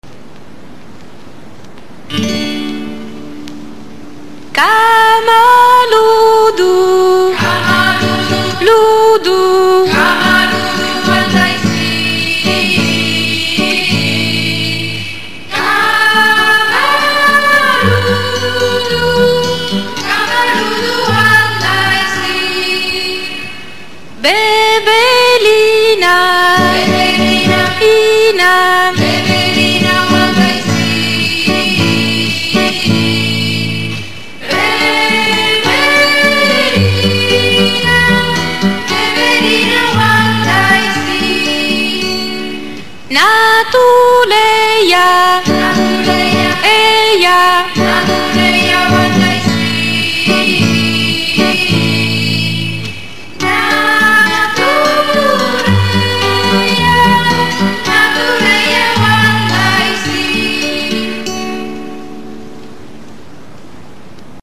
celebre canto di richiamo al fuoco di origine africana